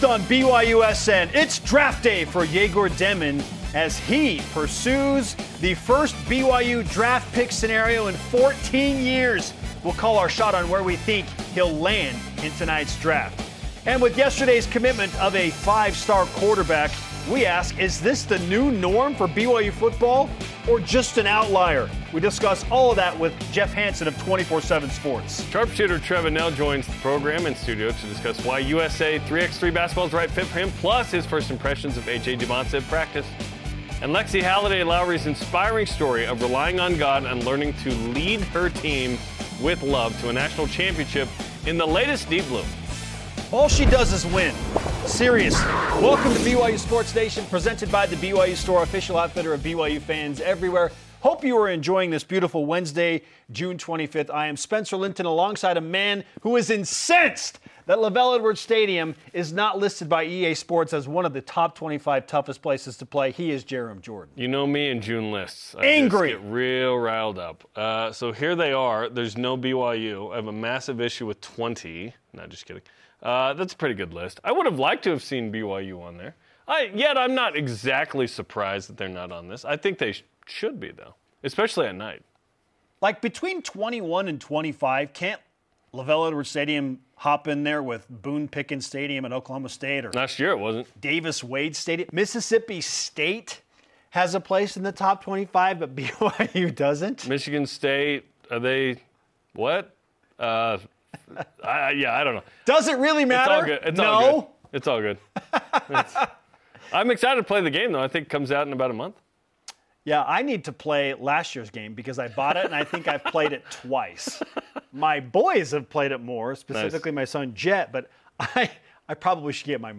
live in the studio